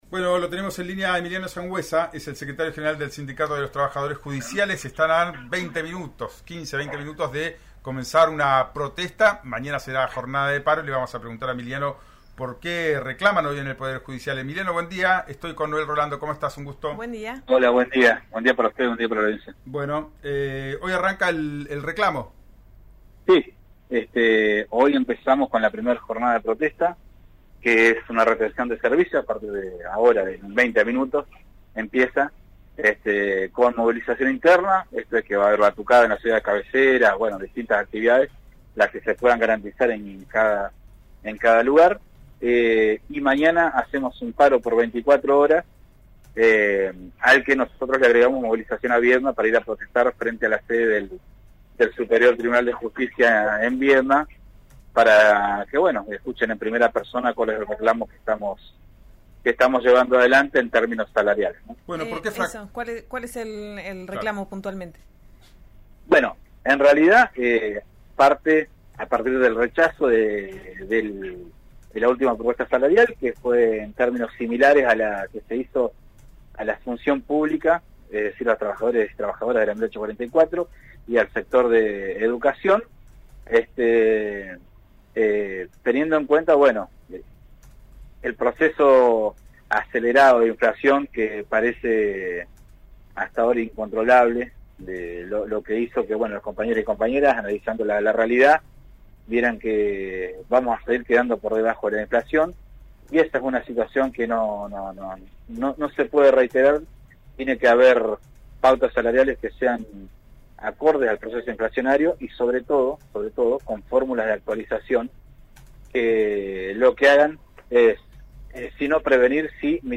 al programa Ya es tiempo que se emite por RN Radio